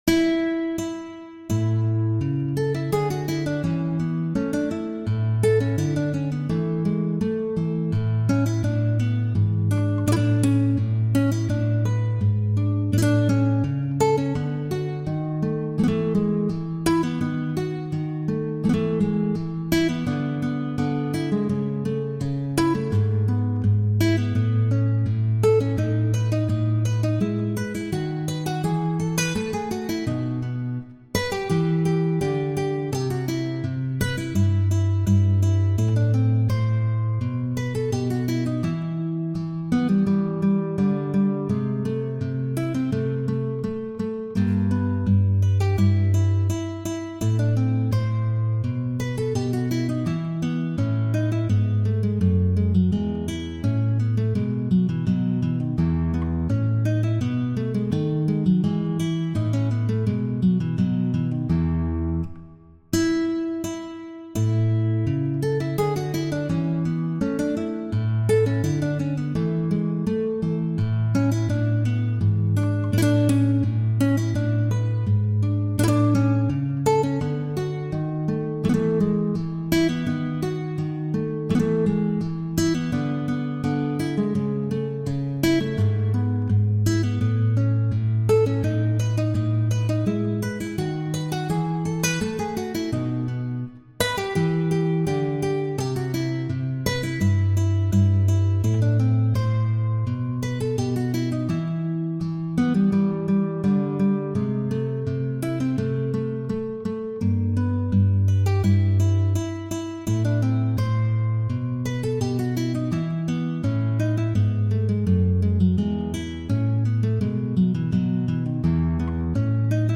Sonate en la majeur (K322).pdf
Sonate-en-la-majeur-K-322.mp3